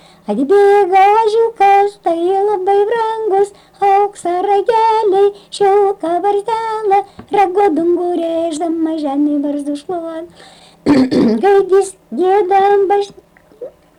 smulkieji žanrai
Atlikimo pubūdis vokalinis